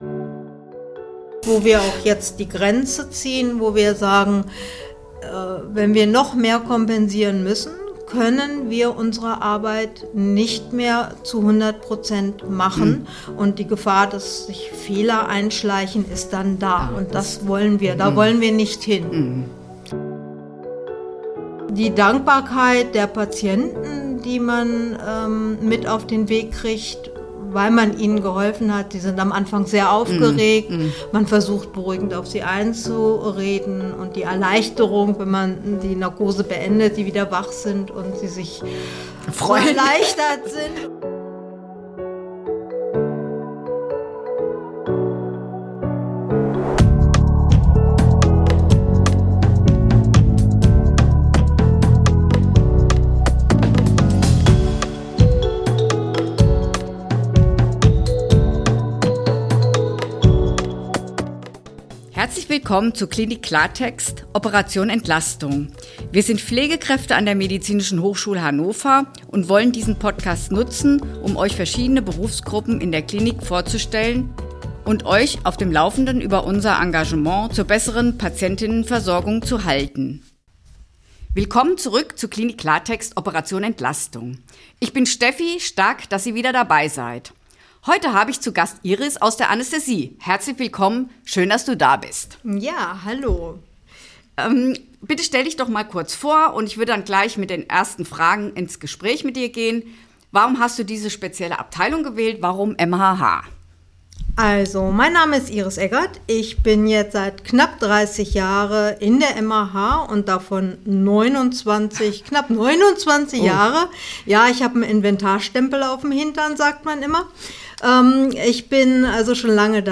Anästhesiefachkraft – Ein Interview